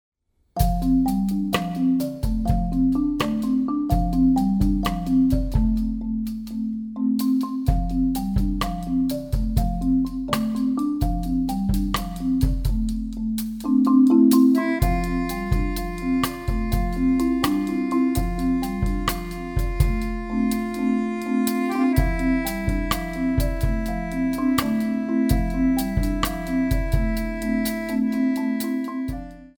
marimba, drumset, hand claps,
accordion